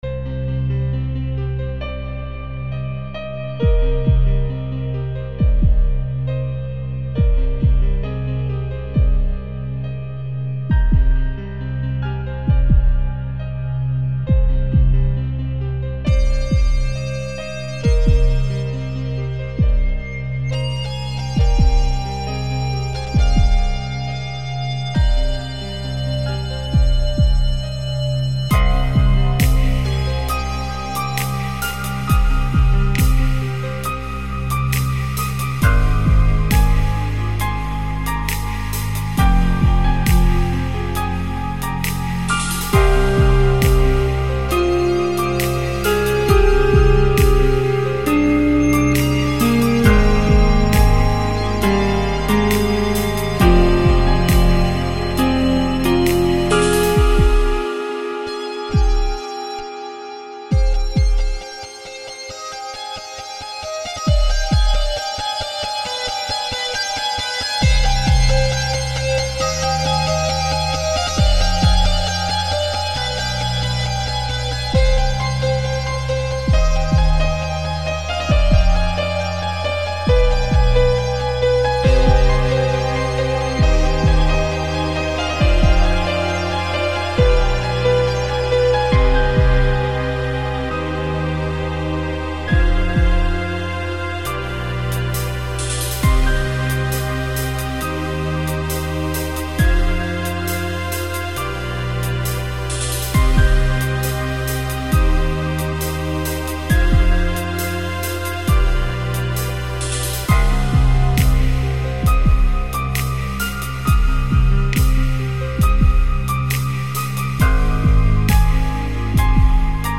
Хороший трек в моей обработке.